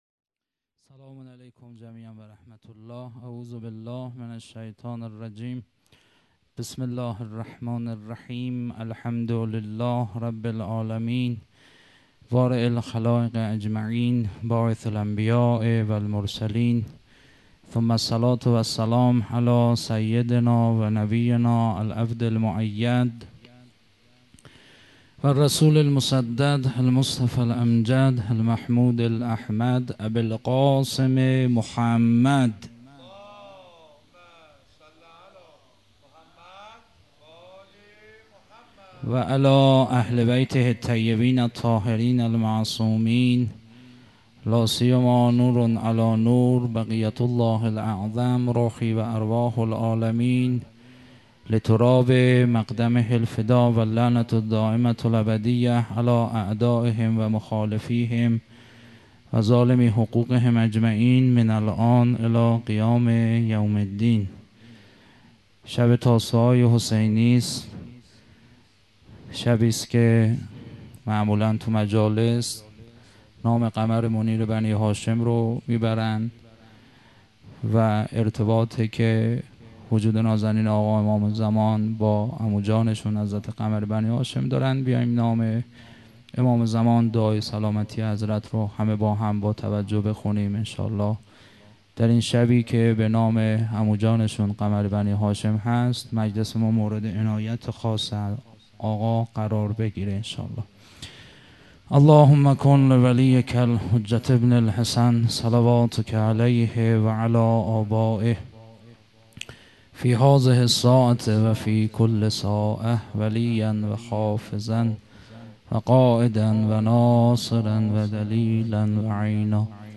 مراسم عزاداری محرم الحرام ۱۴۴۳_شب نهم